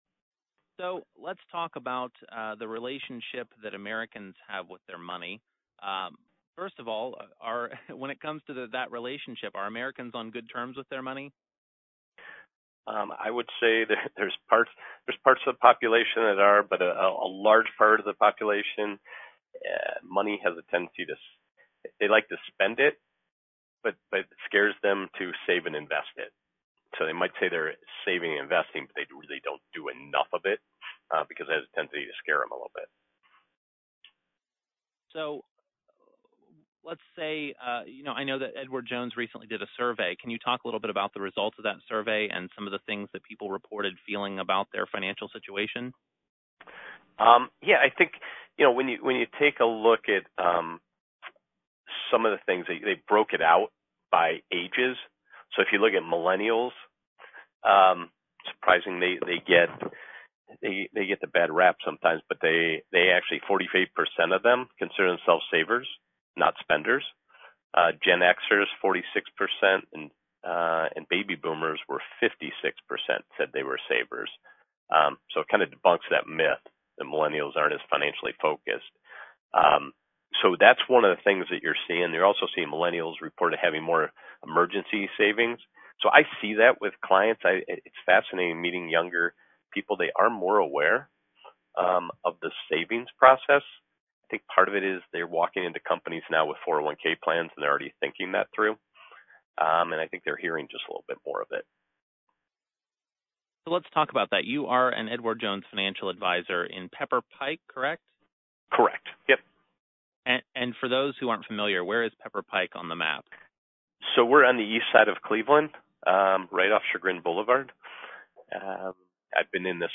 seven minute taped interview on Ohio News Network (Ohio).